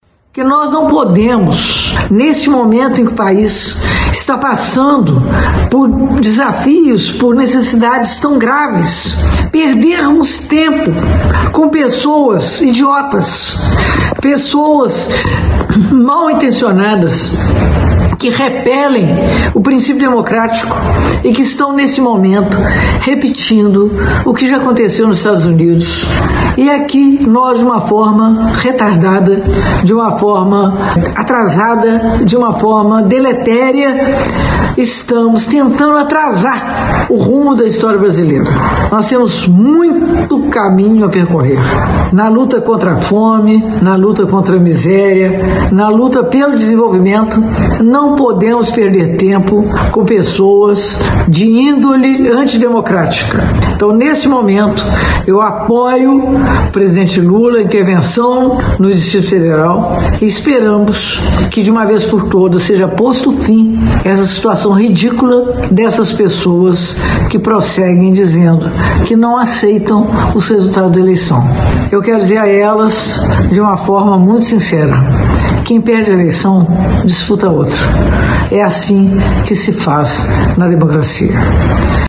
Ouça Margarida Salomão, prefeita de Juiz de Fora